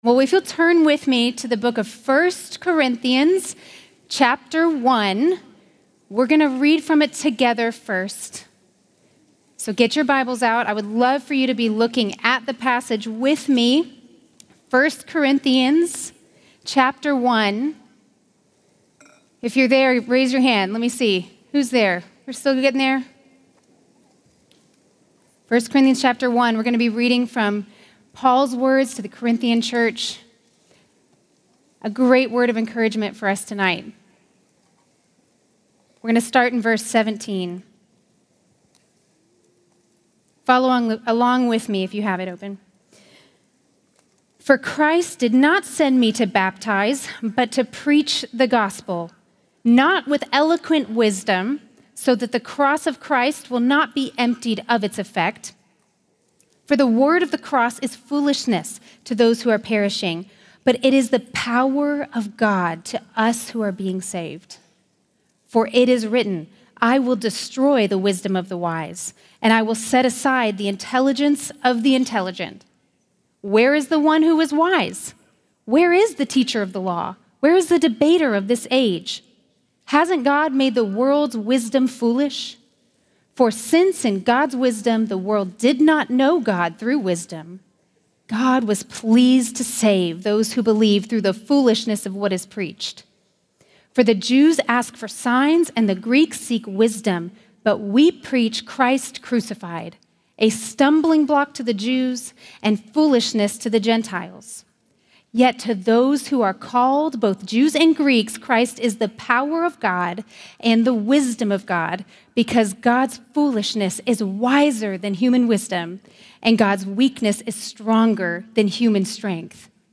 Behold the Power of the Word to Save | True Woman '25 | Events | Revive Our Hearts